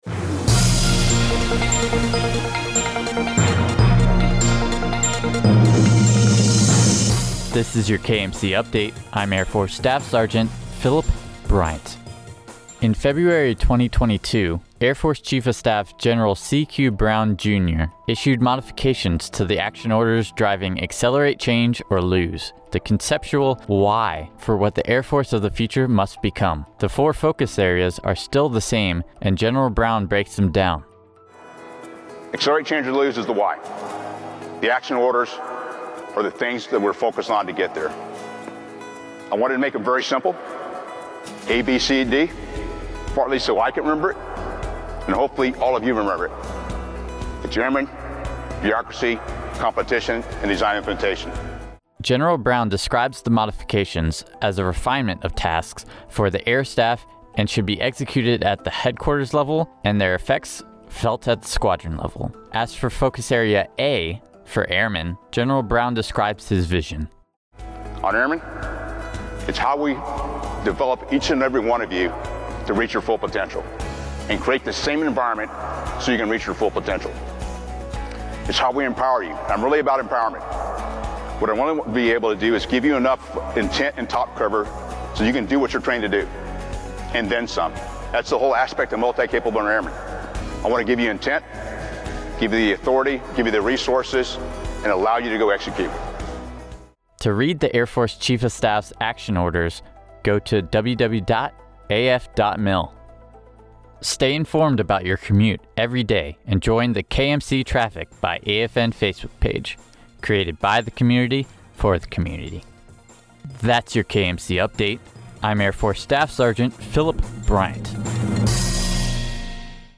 December 2020, U.S. Air Force Chief of Staff Gen. CQ Brown, Jr., issued modifications to the action orders driving “accelerate change or lose”, the conceptual, philosophical “why” for what the Air Force of the future must become. (DMA Radio Newscast